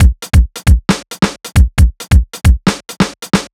Pumped Break 135.wav